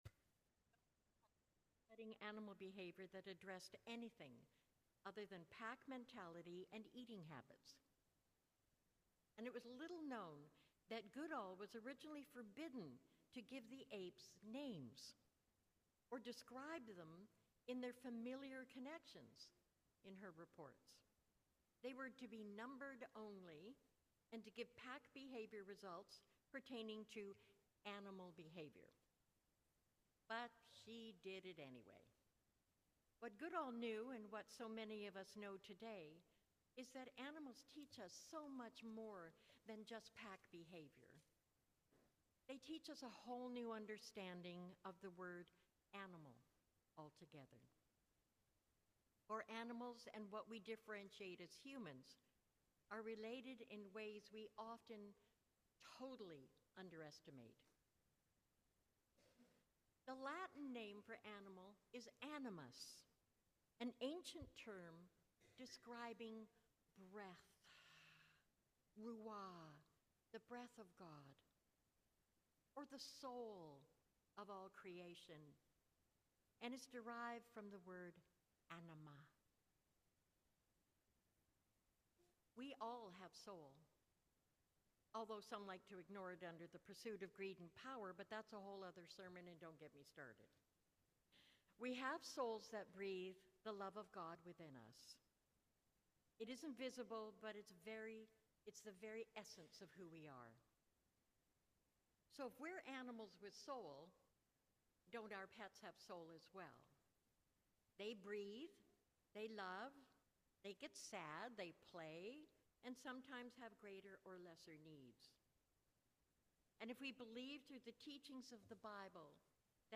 Sermon of the Feast of St. Francis of Assisi